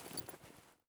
EFT Aim Rattle